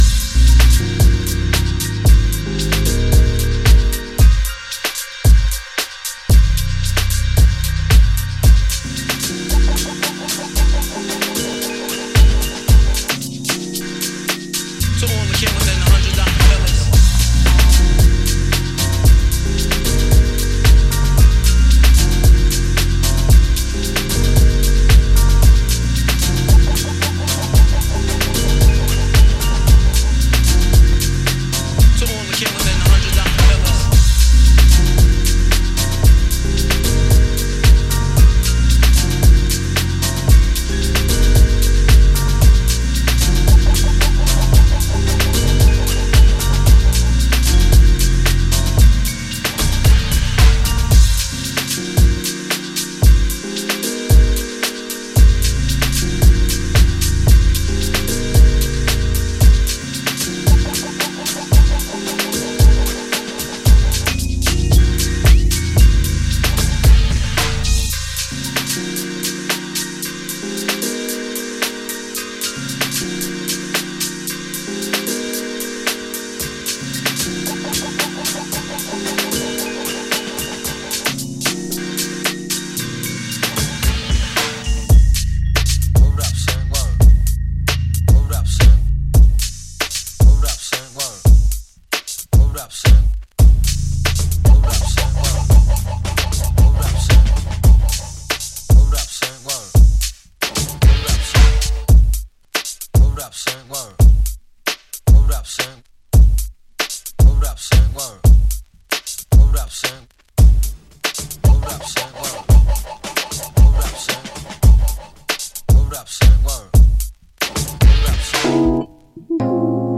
Hiphop, zojuist opgenomen, van een zender die geen playlist weergaf.
Het nummer heeft slechts beperkt tekst, dus Googelen op de songtekst is lastig.